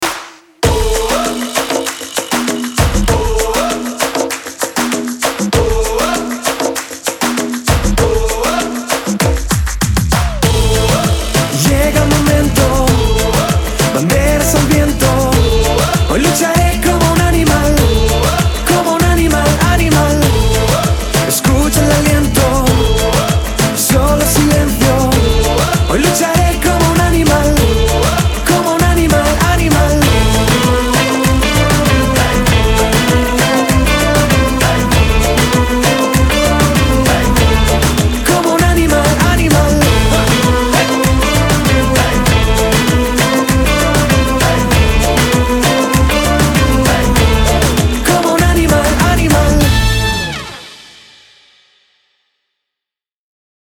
• Качество: 320, Stereo
поп
заводные
Reggaeton
Latin Pop